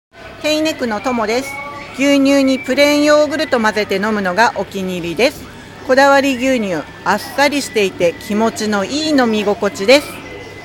試飲いただいたお客様の生の声
リンクをクリックするとこだわり牛乳を試飲いただいた皆様からの感想を聞くことができます。
4月1日（火）15:00～18:00　スーパーアークス 菊水店
お客様の声2